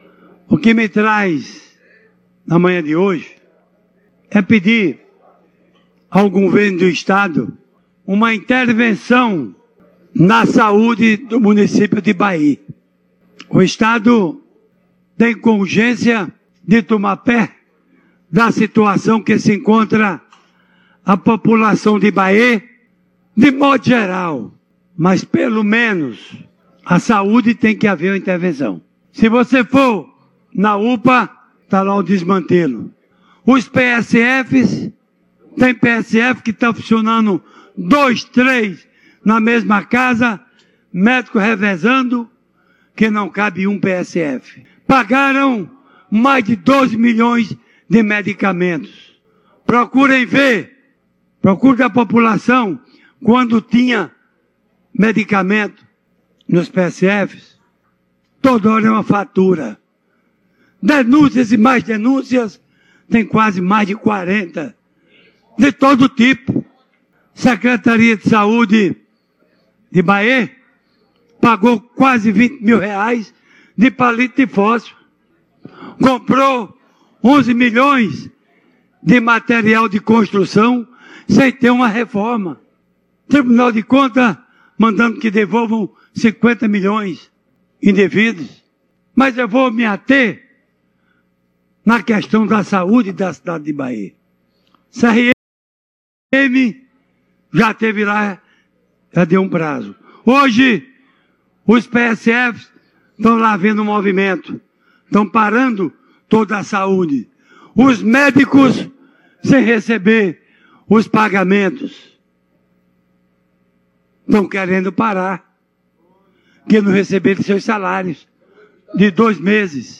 O deputado estadual João Gonçalves (PSB) foi à Tribuna da Assembleia Legislativa da Paraíba (ALPB) pedir intervenção do Governo do Estado da Paraíba no setor da Saúde do Município de Bayeux, localizado na Região Metropolitana de João Pessoa, a Capital do Estado.